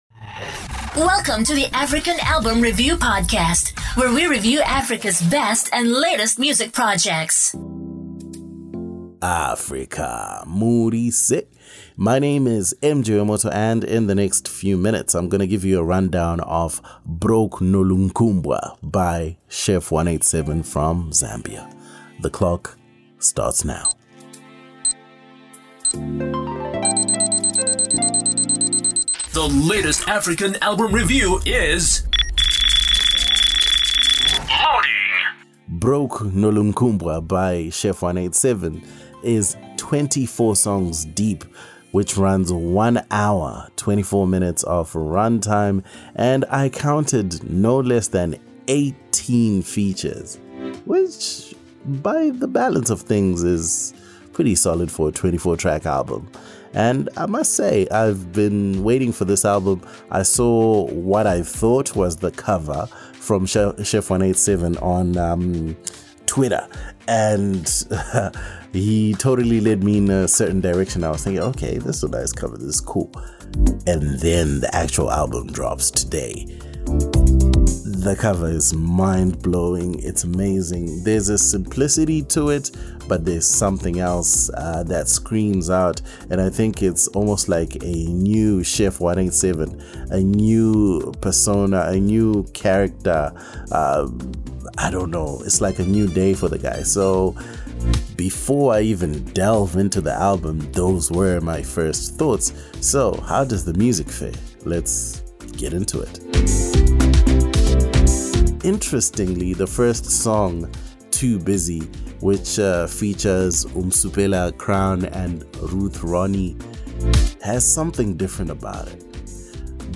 Chef 187 – Broke Nolunkumbwa ALBUM REVIEW Zambia ~ African Album Review Podcast